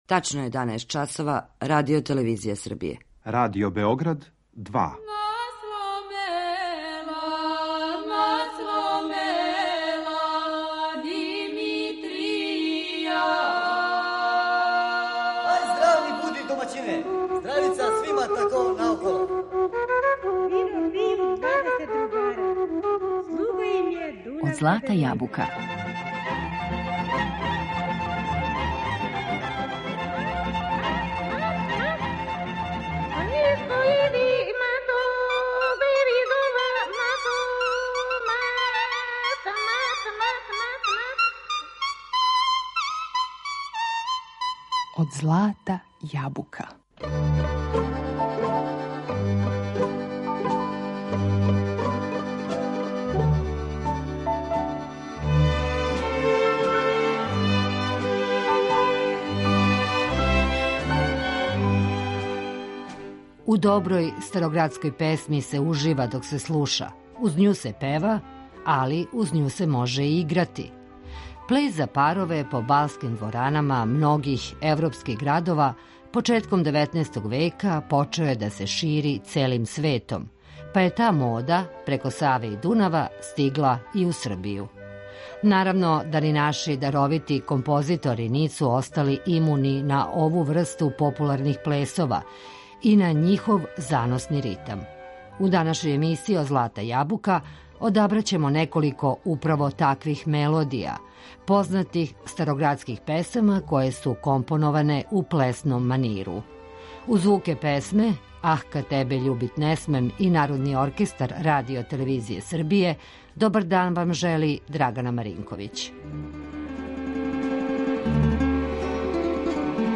Староградска песма
У данашњој емисији Од злата јабука одабраћемо неколико таквих мелодија познатих староградских песама које су компоноване у плесном маниру.